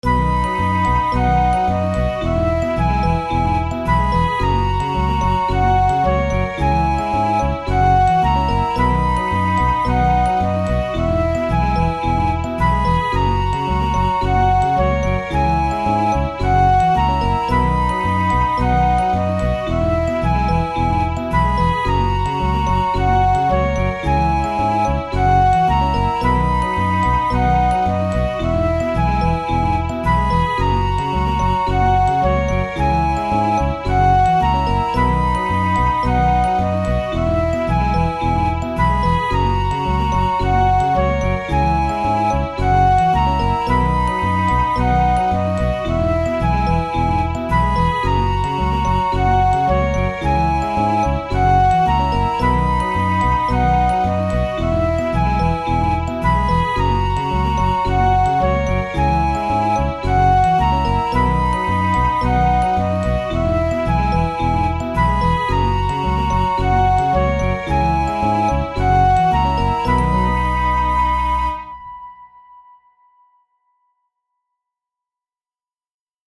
明るい・爽やか
日常の楽しいシーンをイメージした超シンプルな曲。
A, Bともに音色は一緒です。